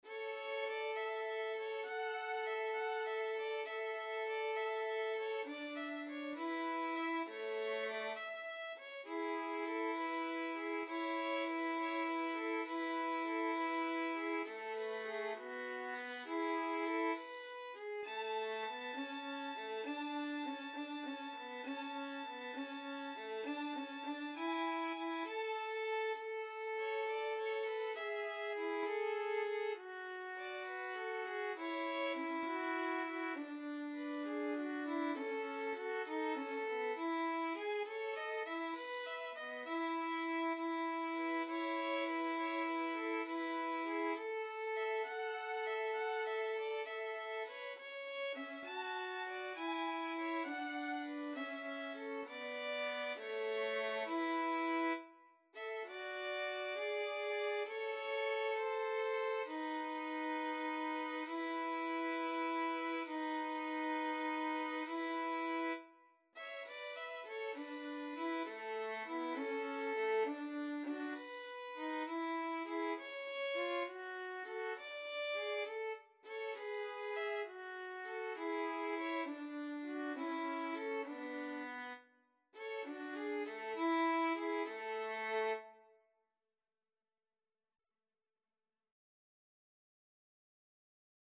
12/8 (View more 12/8 Music)
Largo
Violin Duet  (View more Intermediate Violin Duet Music)
Classical (View more Classical Violin Duet Music)